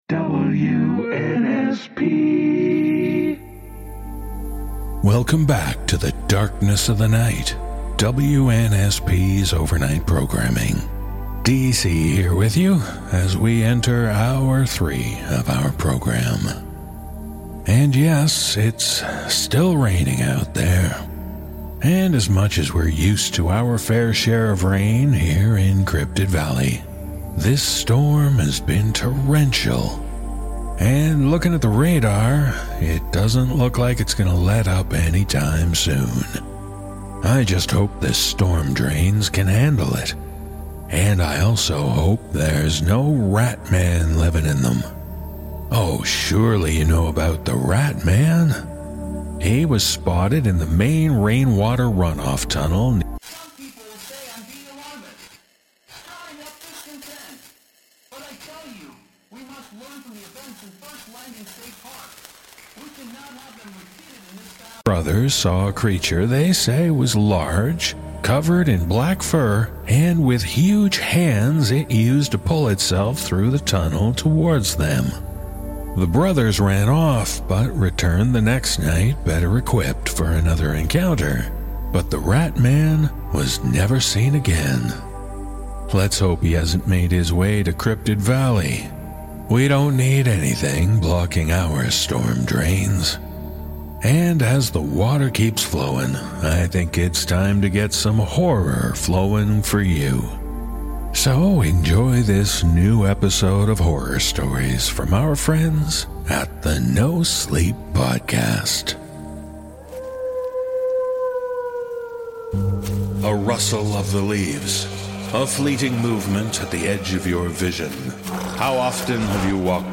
The NoSleep Podcast is a multi-award winning anthology series of original horror stories, with rich atmospheric music to enhance the frightening tales.